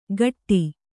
♪ gaṭṭi